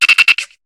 Cri de Grainipiot dans Pokémon HOME.